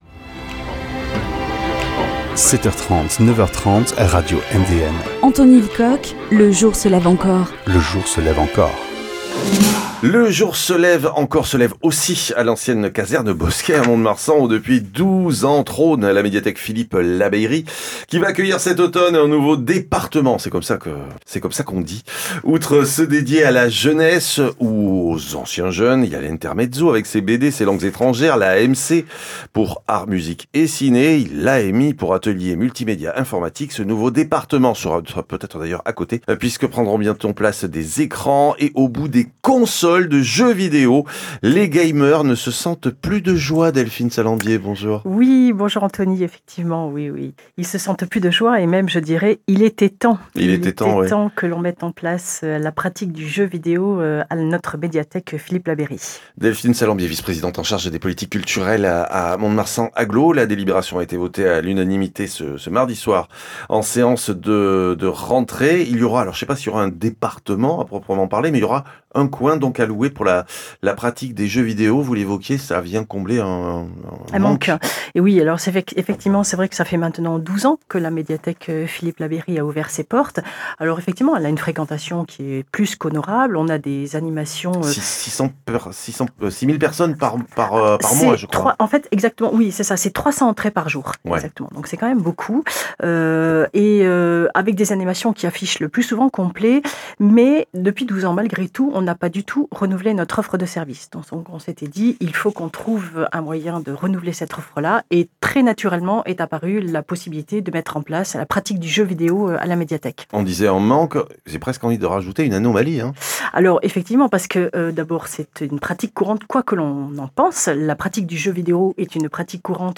3 consoles, une trentaine de jeux les plus prisés des gamers mais aussi des casques à réalité virtuelle : la Médiathèque Philippe Labeyrie accueillera au printemps 2025 cet espace destiné à attirer les adolescents… et probablement quelques uns de leurs parents. Projections – avec Delphine Salembier, vice-présidente de Mont de Marsan Agglo en charge des politiques culturelles.